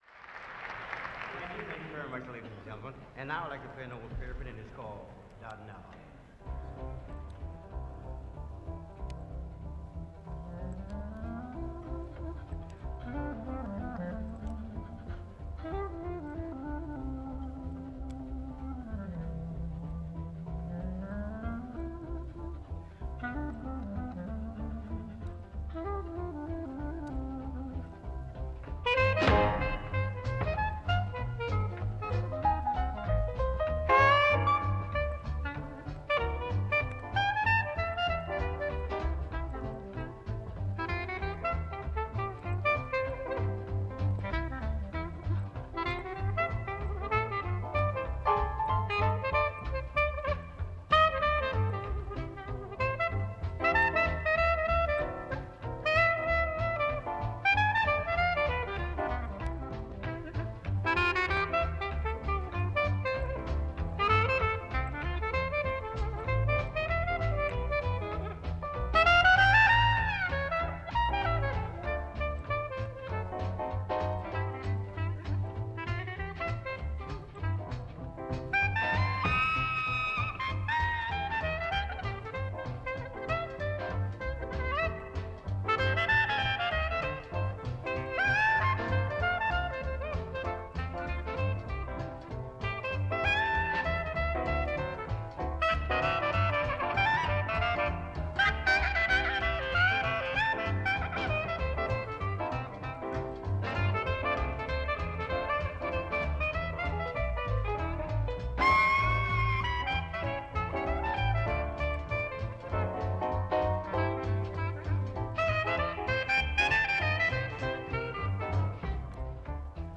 recorded live during a European tour
Clarinet